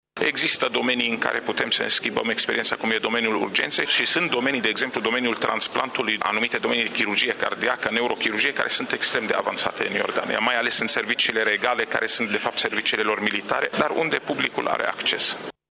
Serviciile medicale regale sau militare din Iordania sunt foarte avansate, spune Arafat, mai ales în domeniul chirurgiei și transplantului.